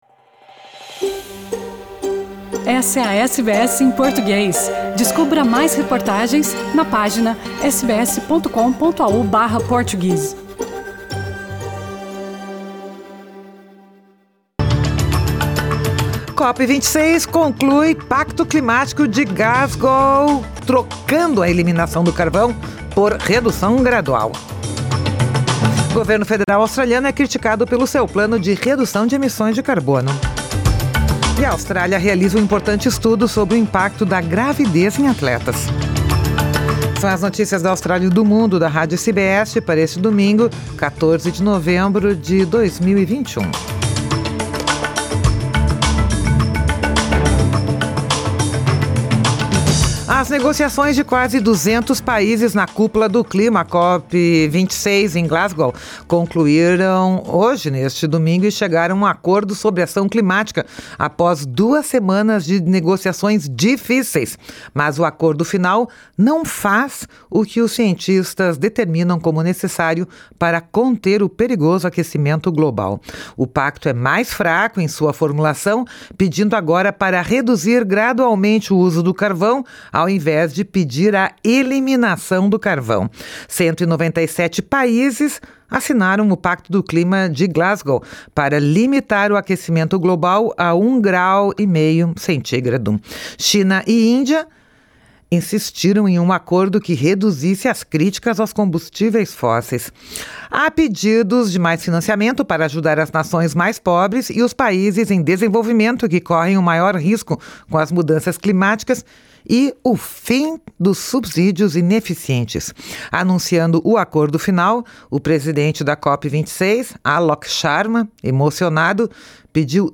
COP26 conclui Pacto Climático de Glasgow trocando "eliminação" do carvão por "redução gradual". Governo Federal australiano é criticado pelo seu plano de redução de emissões. Austrália realiza importante estudo sobre o impacto da gravidez em atletas. São as notícias da Austrália e do Mundo da Rádio SBS para este domingo, 14 de novembro de 2021.